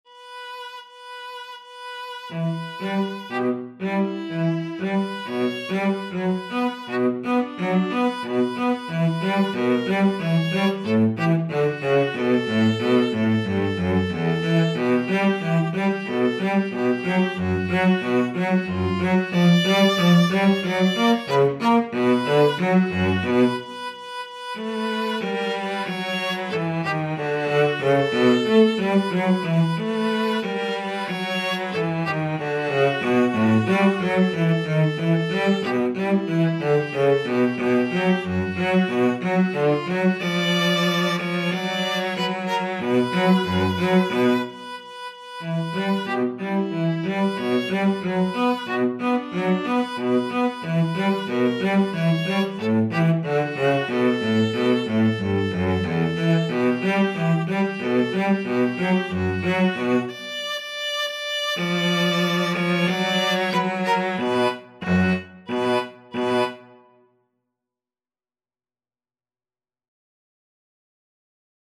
Steadily (first time) =c.60
world (View more world Viola-Cello Duet Music)